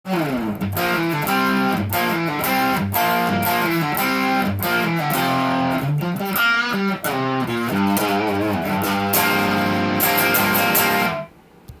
ロックなリフやソロも歪ませると気持ちよく音が伸びます。
yamaha.pashi_.hizumi.m4a